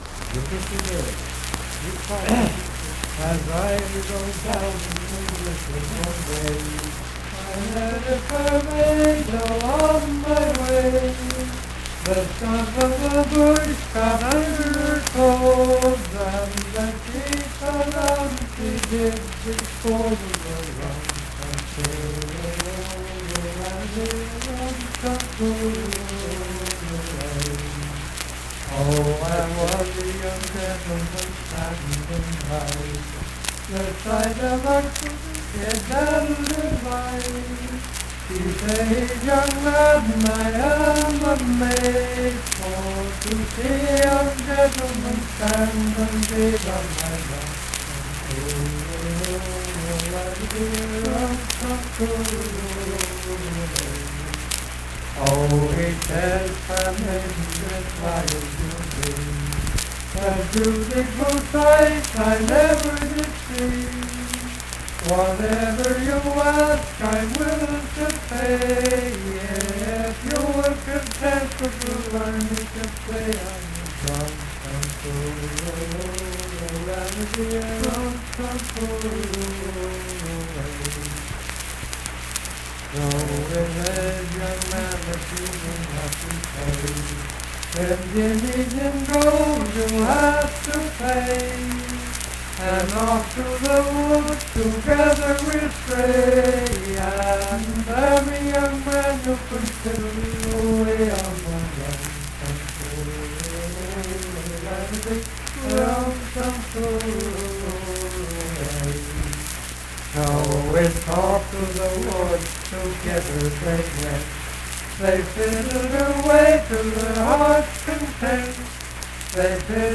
Unaccompanied vocal music
Bawdy Songs
Voice (sung)